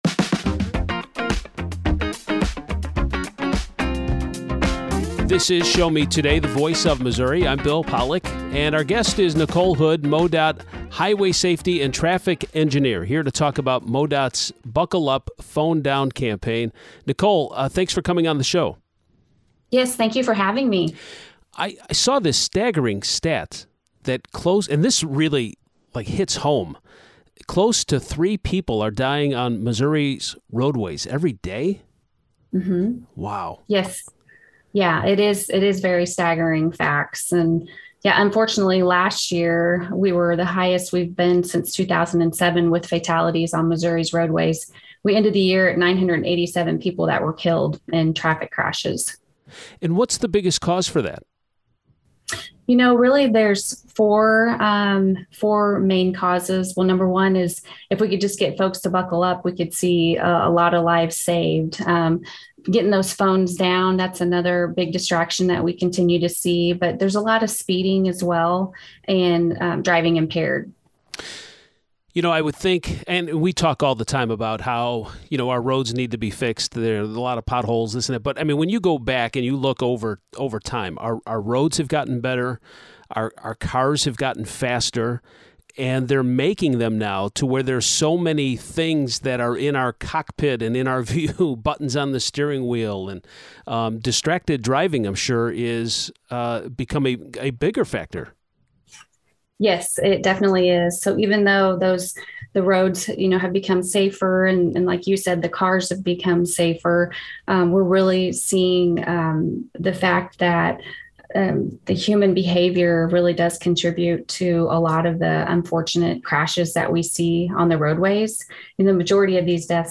joins Show Me Today to talk about the effort to save lives on Missouri roadways.